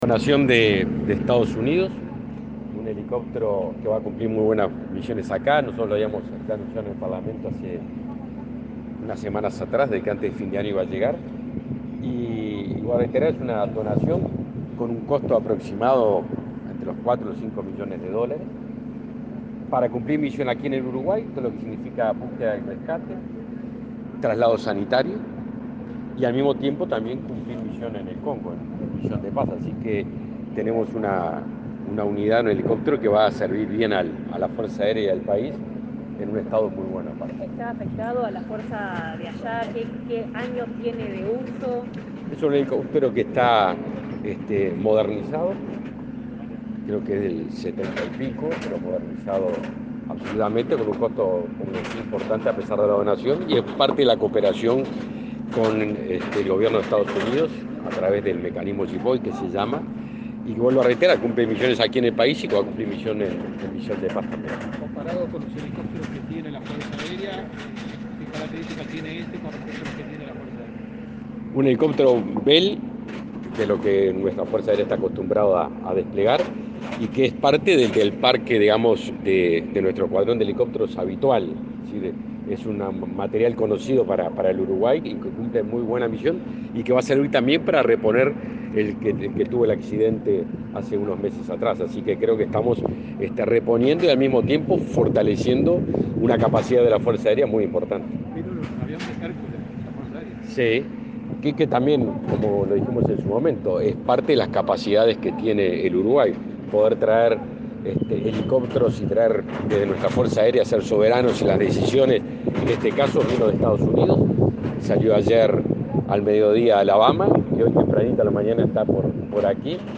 Declaraciones a la prensa del ministro de Defensa, Javier García
El ministro Javier García brindó detalles a la prensa de este aporte para el país.
garcia prensa.mp3